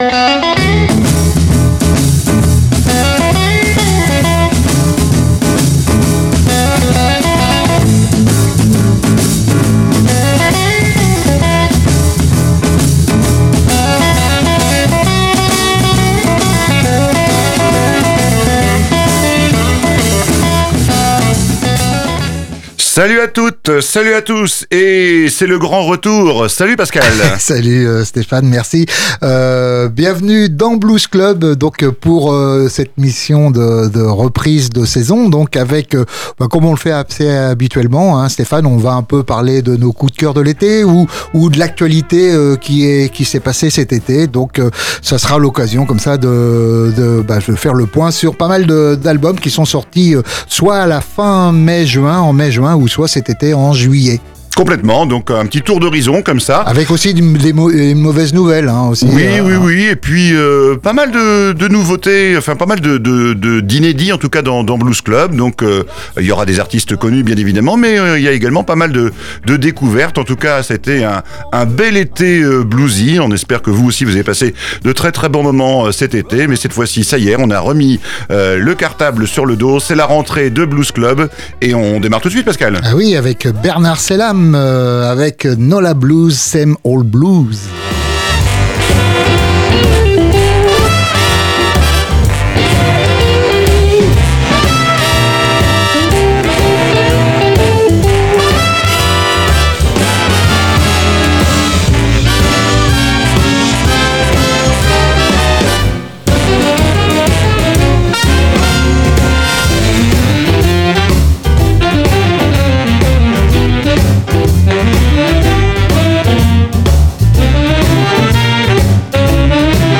Pour cette émission de rentrée, Blues Club vous propose de partager avec nous les morceaux de Blues qui ont ensoleillé notre été !